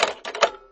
Add payphone sound files
old-payphone-hangup.mp3